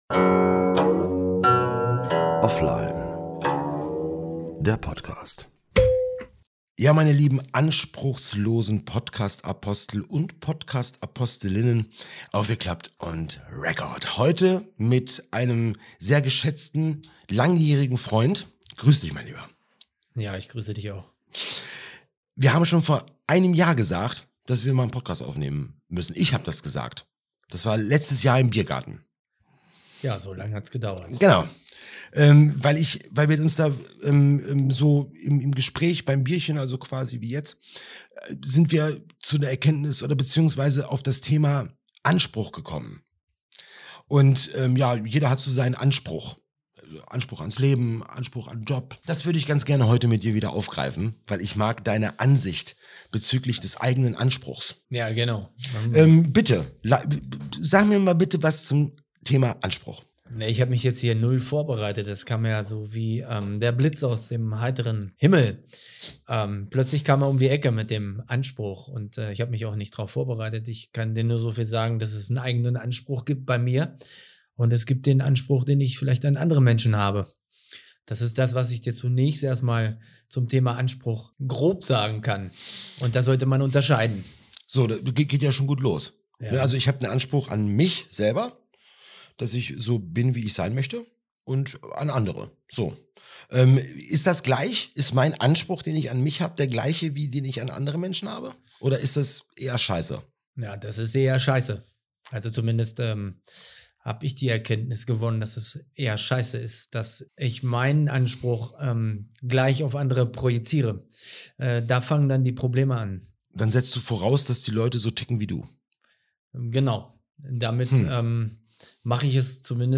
langjärigen Freund zum Thema Anspruch!